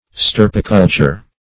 Search Result for " stirpiculture" : The Collaborative International Dictionary of English v.0.48: Stirpiculture \Stir"pi*cul`ture\, n. [L. stirps, stirpis, stem, stock, race + cultura culture.] The breeding of special stocks or races.